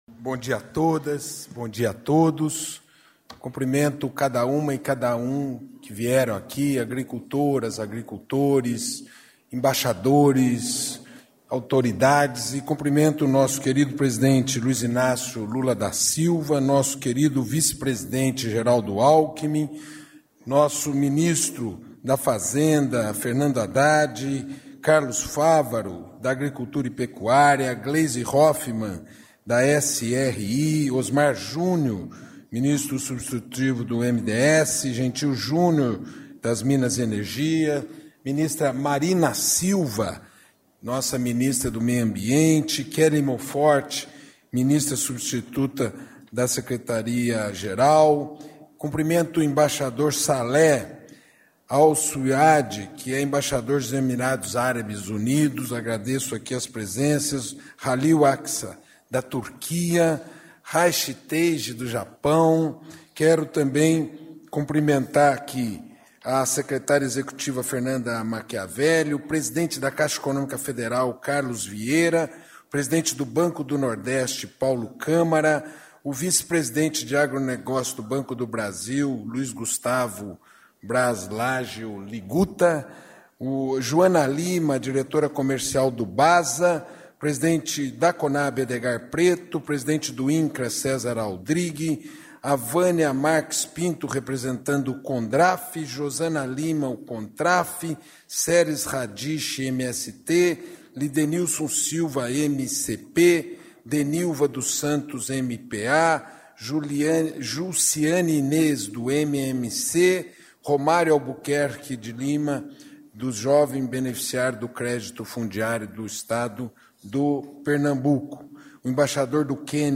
Íntegra da entrevista concedida pelo ministro do Desenvolvimento Agrário e Agricultura Familiar, Paulo Teixeira, nesta segunda-feira (30), no Palácio do Planalto, após lançamento do Plano Safra da Agricultura Familiar 2025/2026.